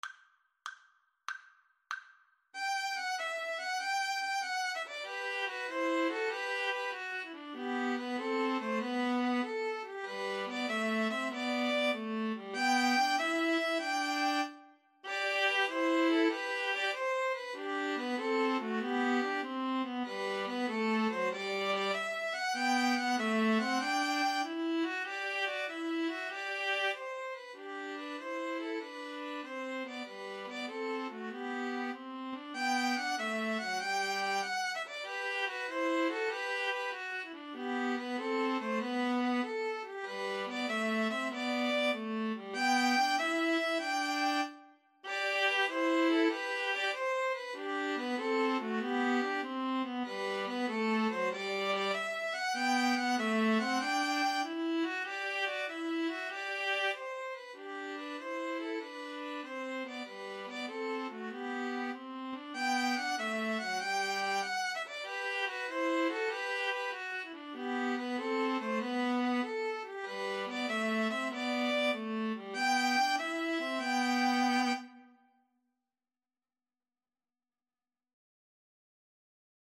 Classical Trad. Sumer is icumen in (Summer is a-coming in) String trio version
3/8 (View more 3/8 Music)
G major (Sounding Pitch) (View more G major Music for String trio )
Happily .=c.96